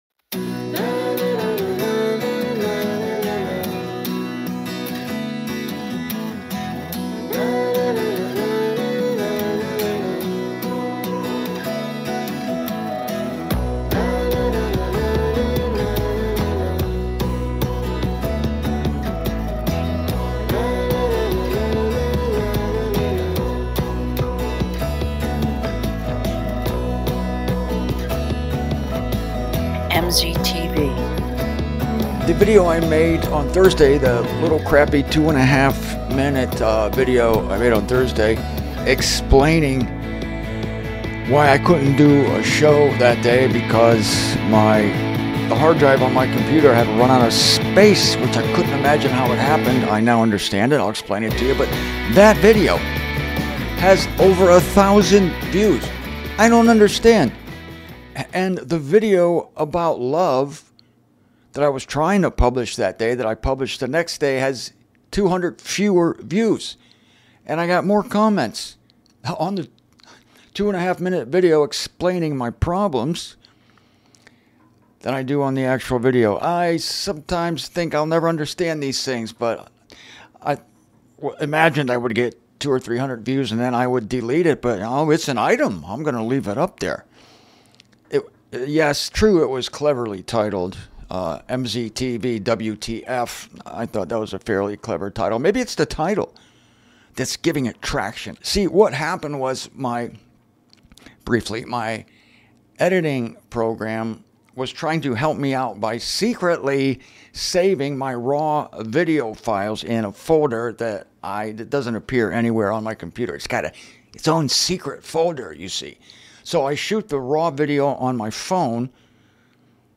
Today I read a short excerpt from this book, from a chapter titled "What is Love?"